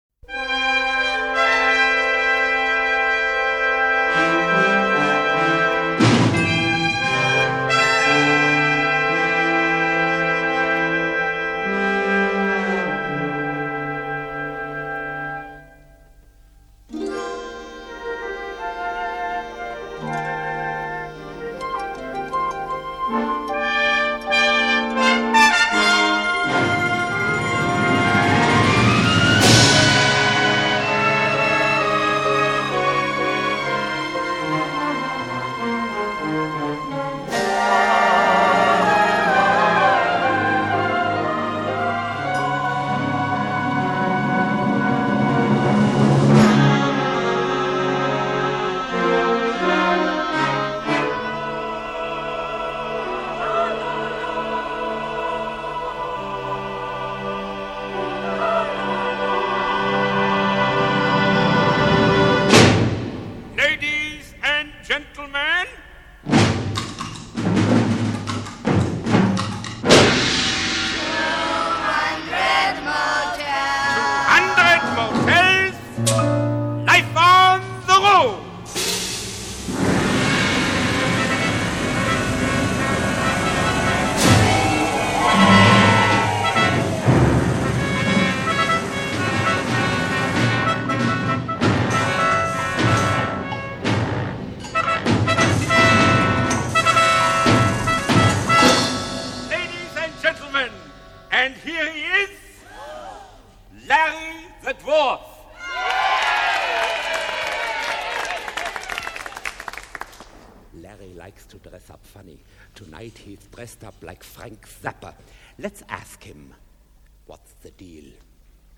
Soundtrack, Rock, Avantgarde, Classical